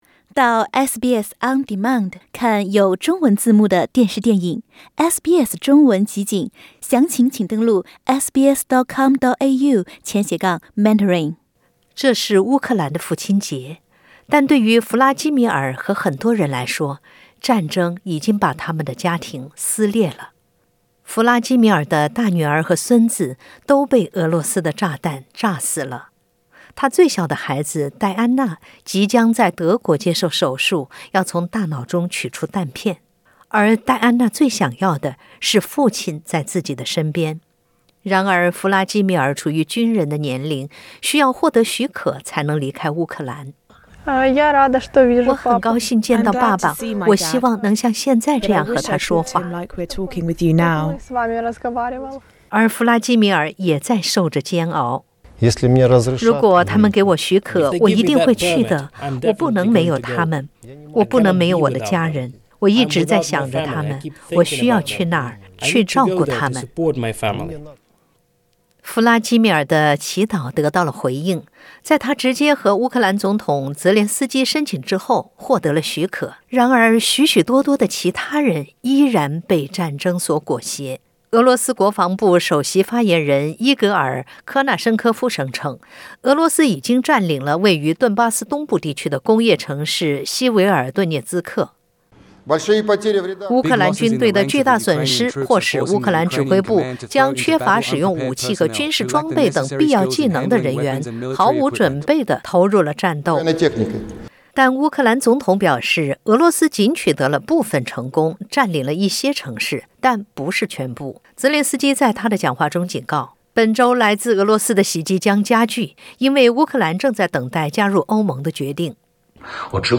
一封密信显示，英国军方领导人已通知其部队做好在欧洲与俄罗斯作战的准备。 这封信是在北约秘书长警告乌克兰战争可能会持续数年之际发出的。(点击上图收听报道)